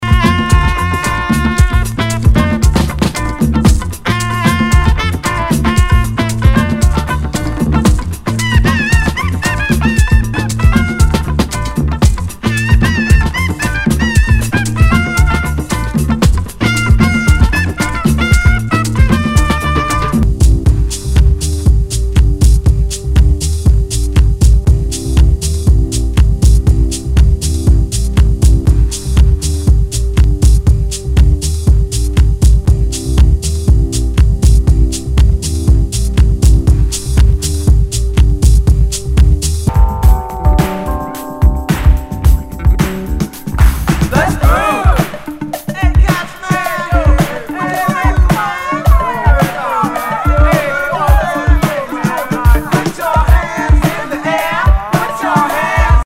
HOUSE/TECHNO/ELECTRO
ナイス！ファンク / ハウス！